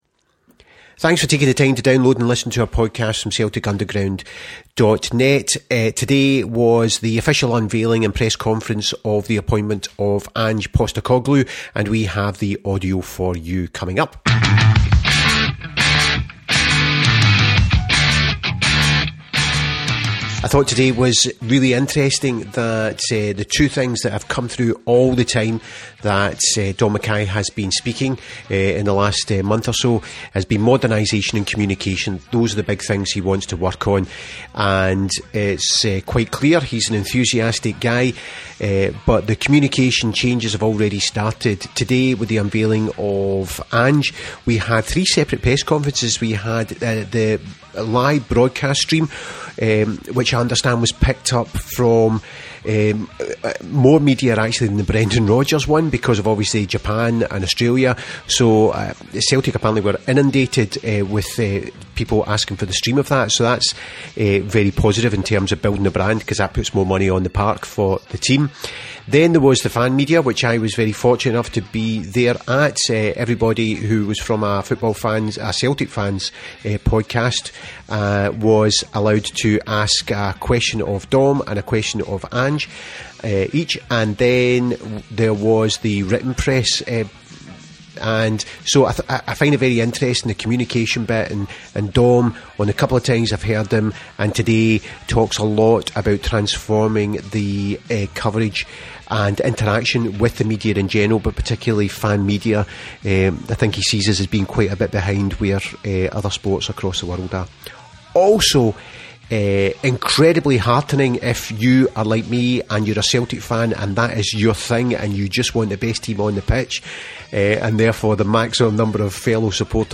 We have the fan media questions first followed by the broadcast media press conference.